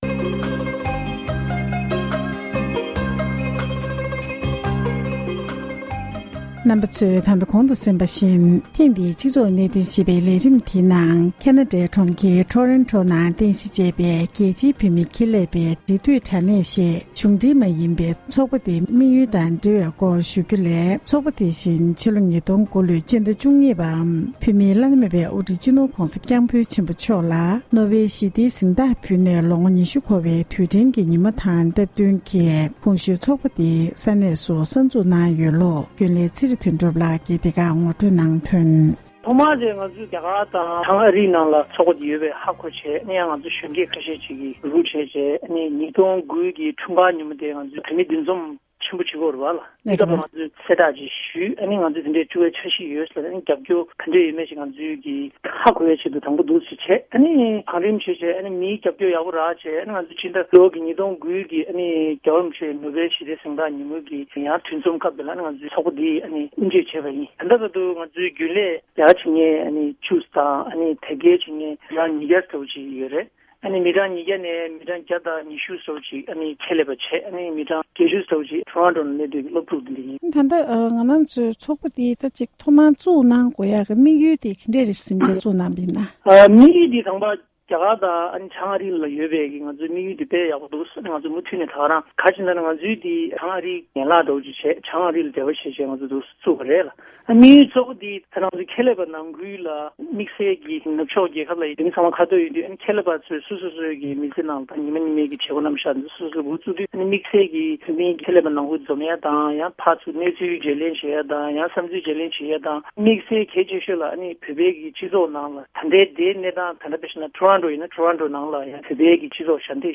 འབྲེལ་ཡོད་མི་སྣར་གནས་འདྲི་ཞུས་པ་ཞིག་གསན་རོགས༎